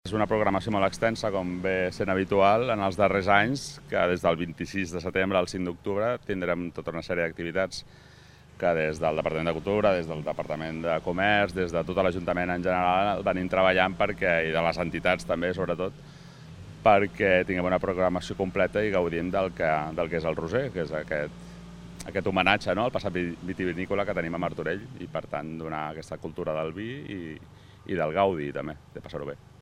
Sergi Corral, regidor de Cultura